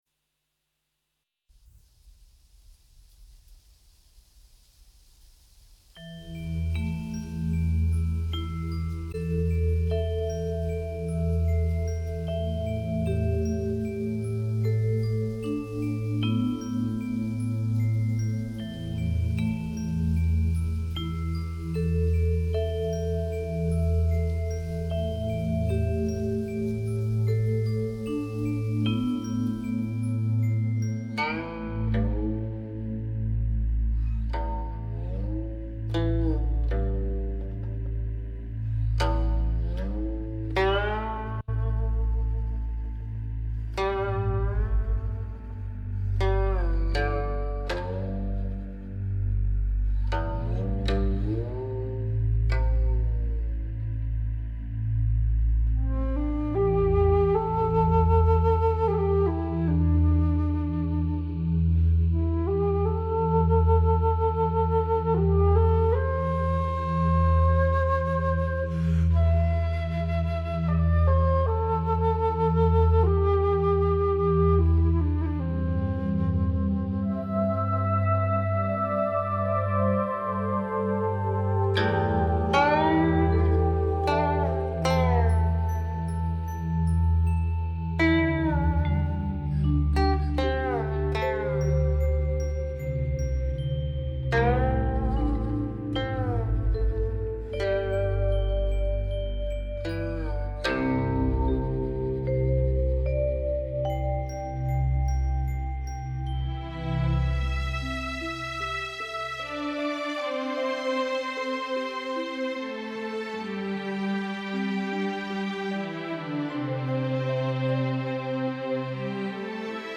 VERSIONE LUNGA SENZA COMANDO PER LA PRATICA